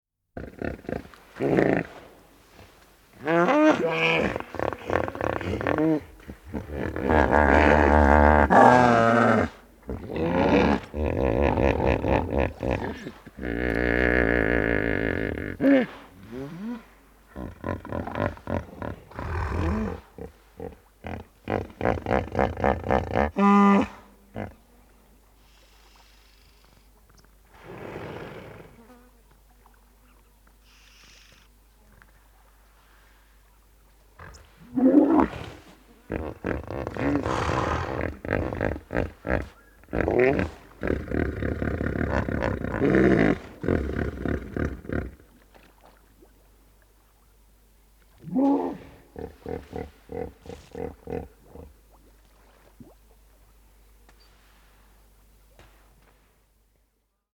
Hipopótamo | SÓNEC | Sonoteca de Música Experimental y Arte Sonoro
Nota de contenido: Bramido
Hipopotamo.mp3